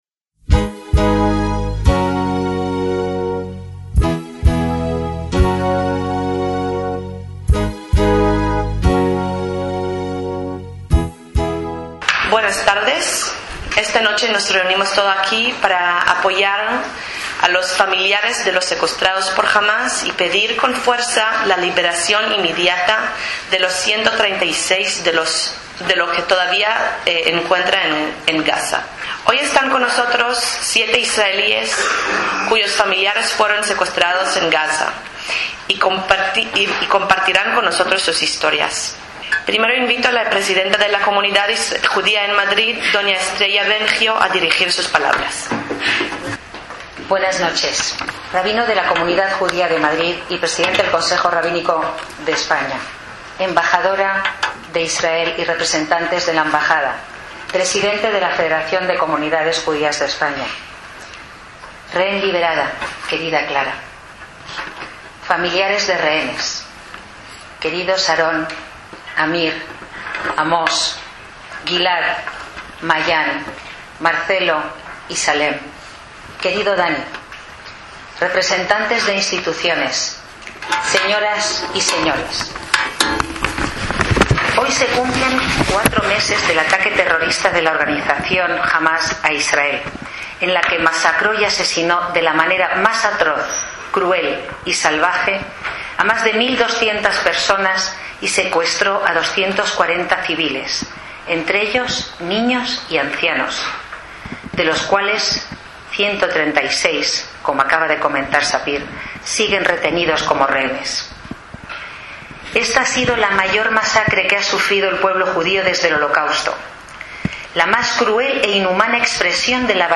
Acto en la Comunidad Judía de Madrid con familiares de los secuestrados por Hamás (CJM, 7/2/2024)
ACTOS EN DIRECTO